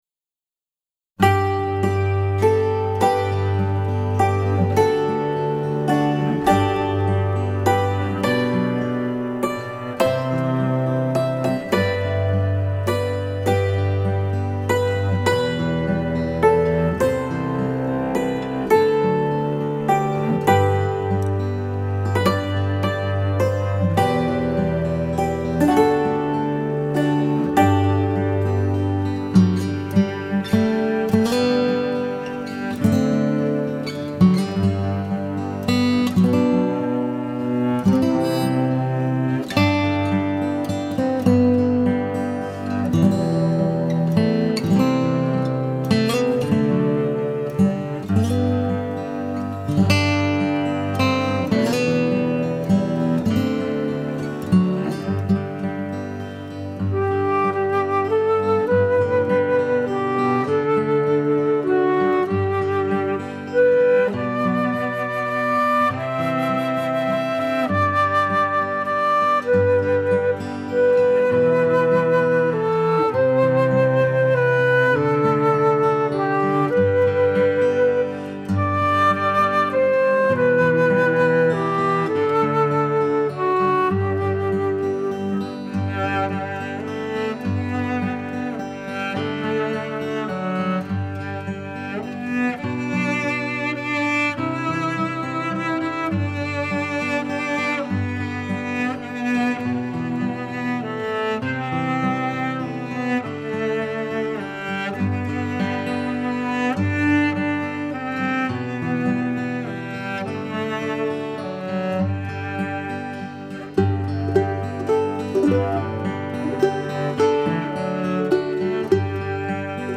DIGITAL SHEET MUSIC - HAMMERED DULCIMER SOLO